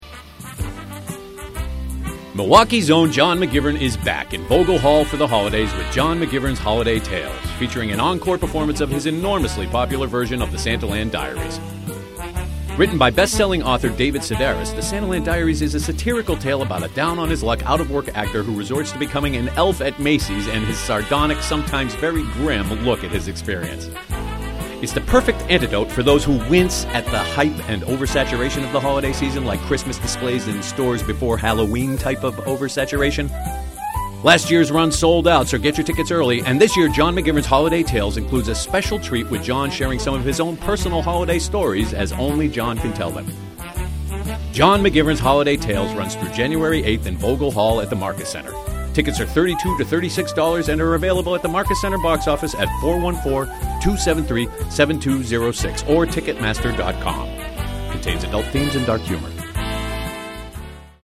Santaland Diaries Radio Commercial